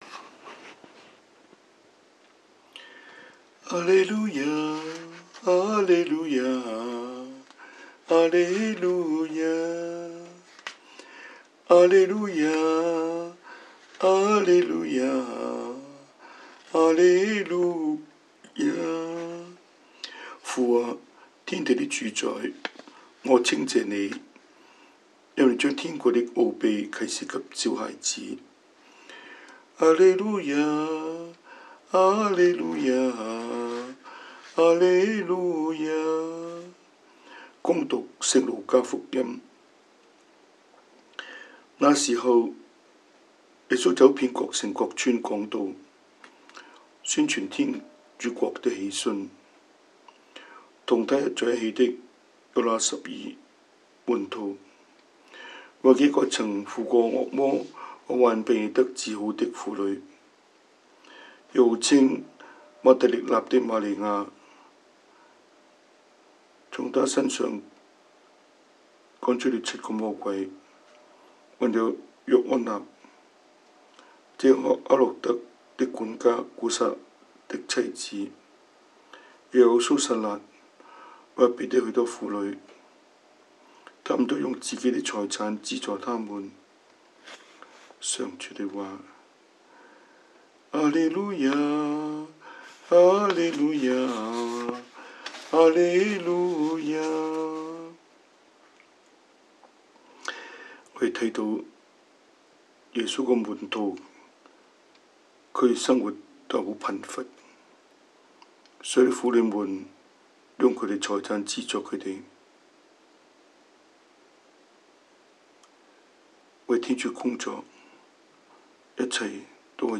Cantonese Homily, Eng Homily